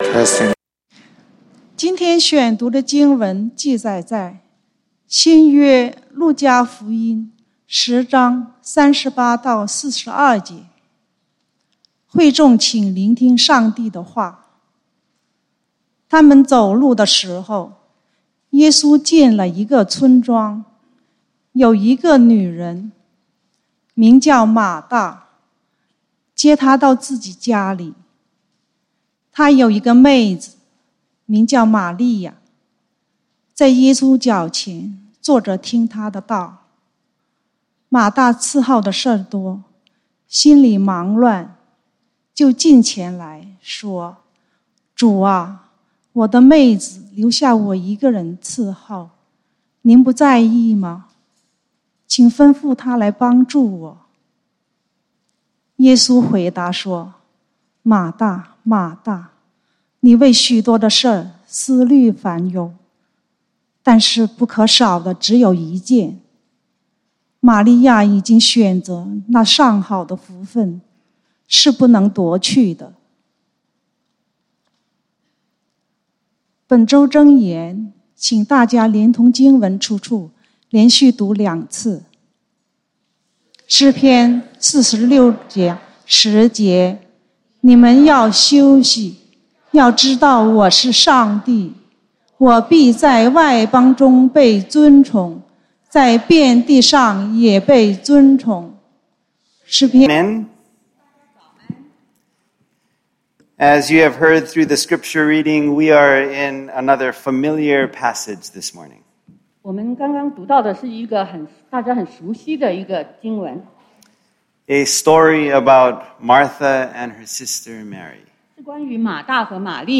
7/17/2022 講道經文：路加福音 Luke 10:38-42 本週箴言：詩篇 Psalms 46:10 你們要休息，要知道我是上帝！